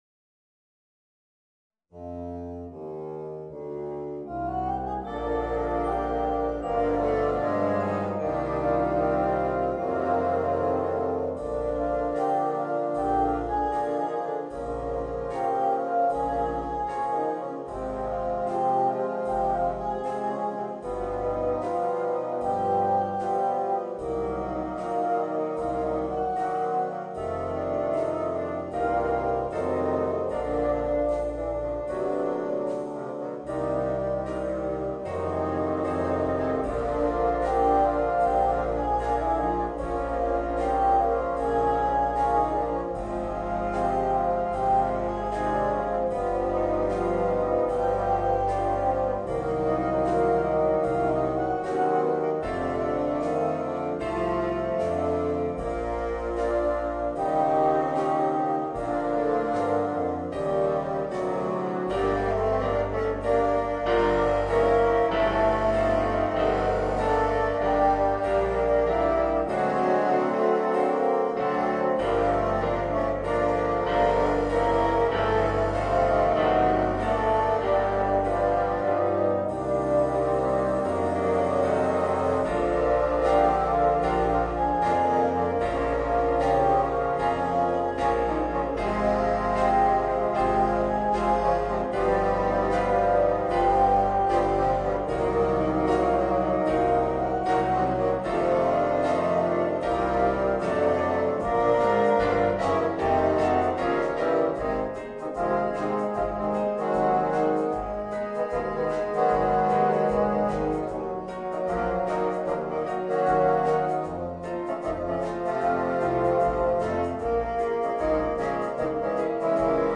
バスーン四重奏